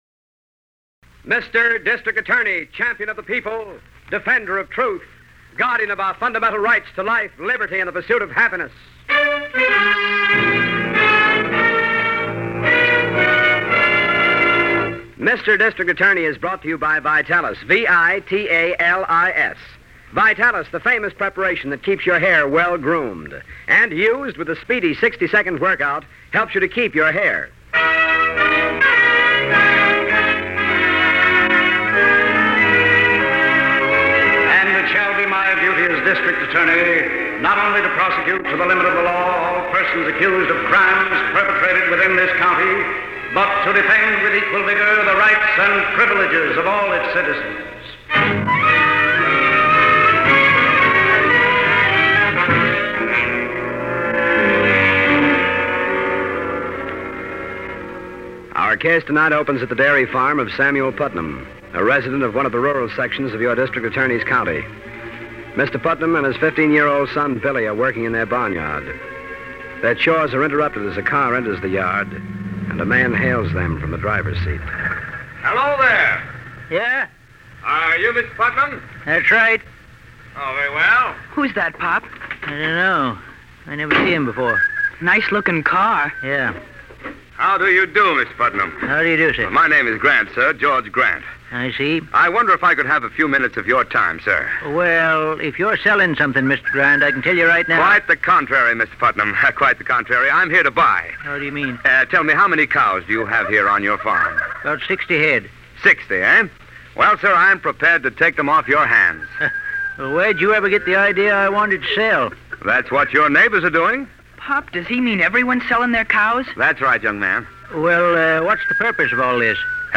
District Attorney is a radio crime drama